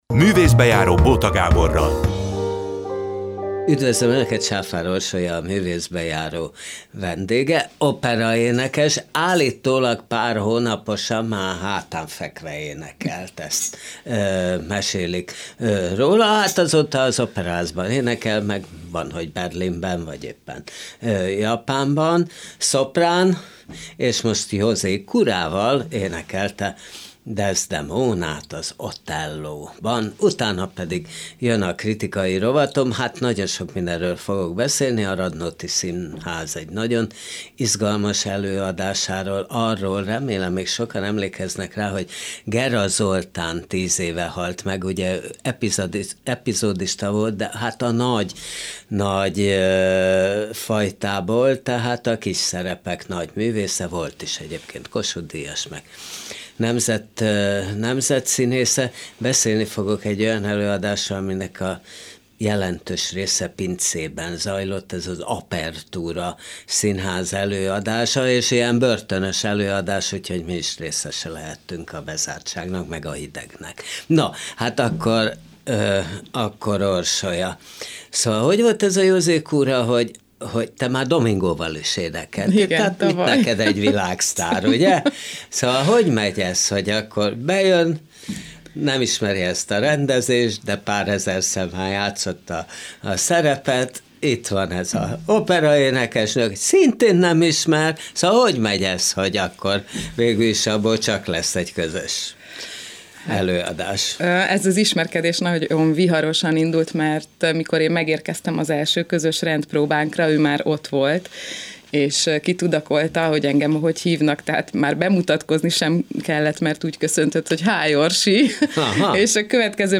1 Simán lehet, hogy még ez-az előbújik a márquezi bőröndből 47:58 Play Pause 27d ago 47:58 Play Pause Lejátszás később Lejátszás később Listák Tetszik Kedvelt 47:58 A Buksó történetében először közönség előtt, a tavaszi Margó Irodalmi Fesztiválon rögzítettük a soron következő beszélgetést.